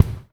04A KICK  -R.wav